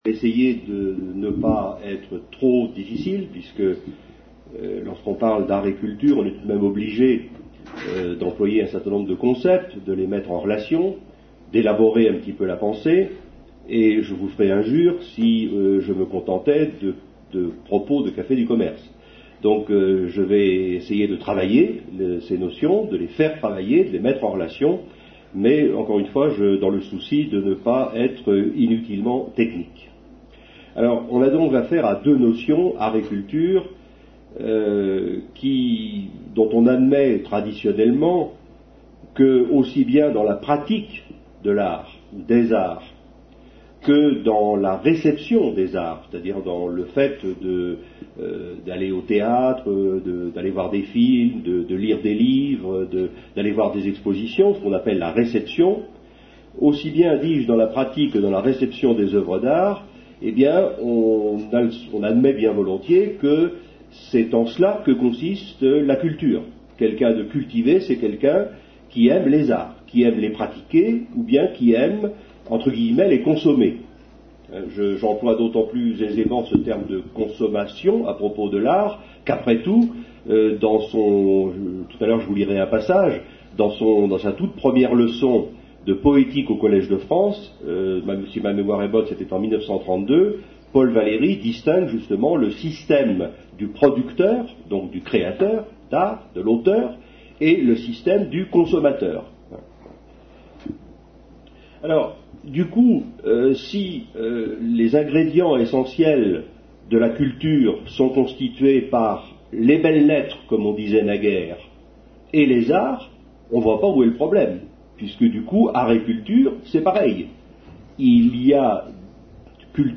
Lycée Pierre et Marie Curie (Menton 06).